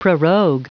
Prononciation du mot prorogue en anglais (fichier audio)
Prononciation du mot : prorogue
prorogue.wav